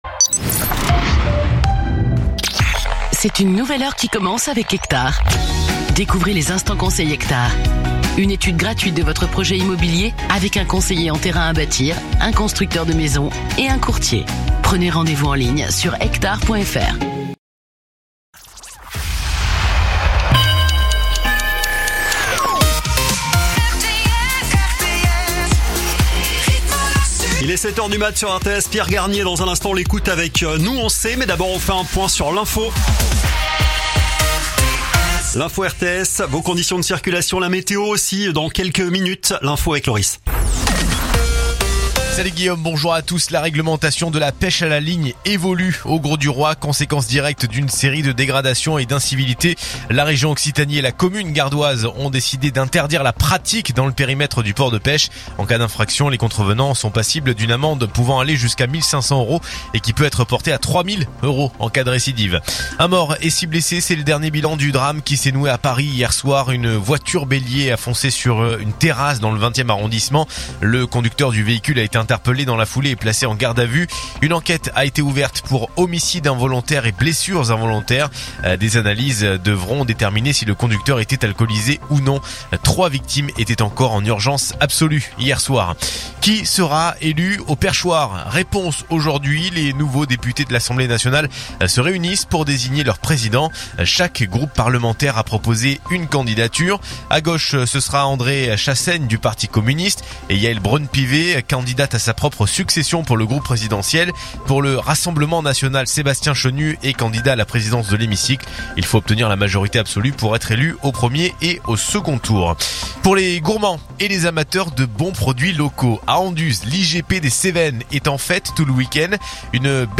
info_nimes_83.mp3